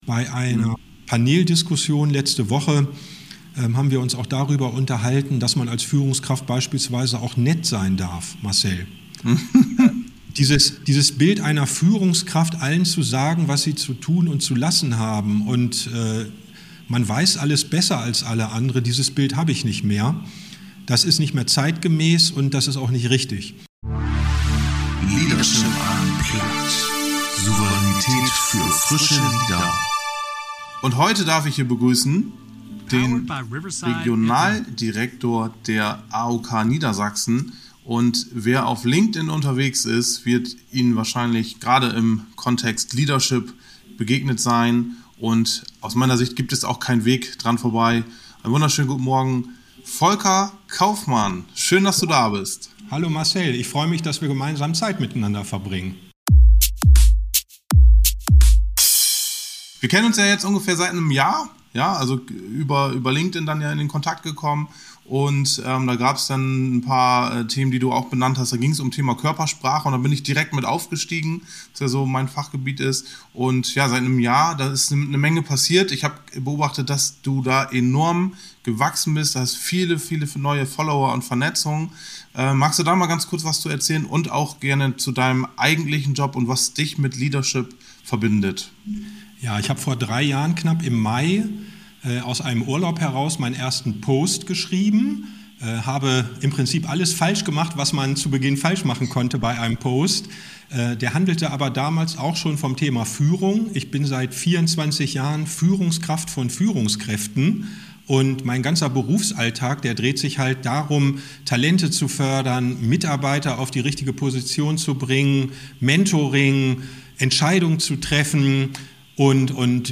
Jedes Gespräch bietet tolle Möglichkeiten, um seine eigene Haltung zu überprüfen. Im diesem Interview zeigt sich der Regionalmanager authentisch und inspiriert mit wertvollen Impulsen.